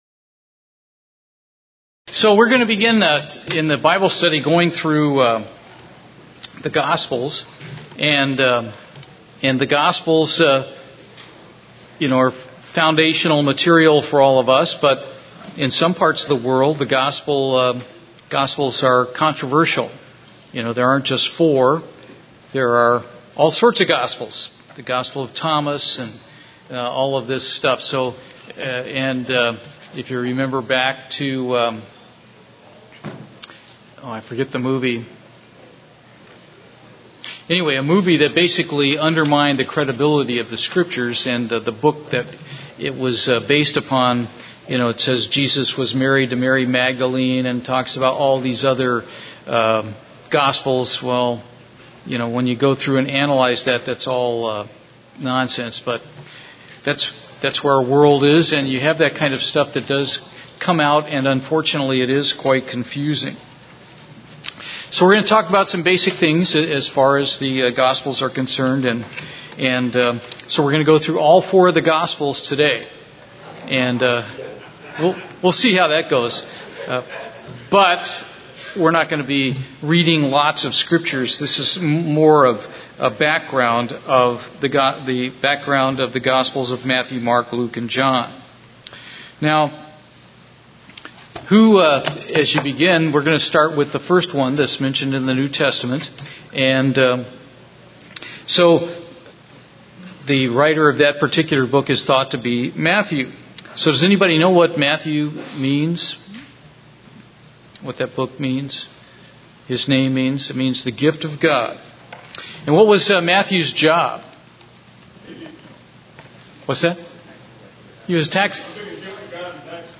Bible study